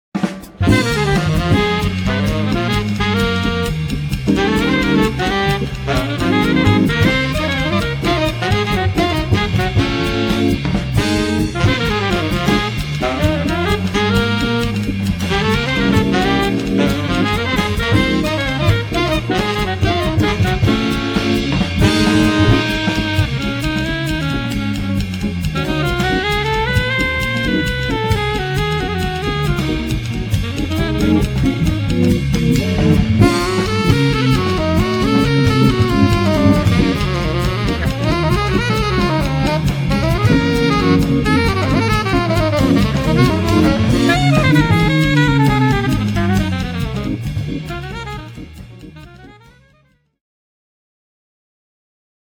the smokey tenor sax
the propulsive alto
organ
alto sax
tenor sax
guitar
drums